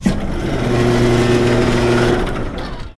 Electric Sliding Door Open, Clunky Slide With Motor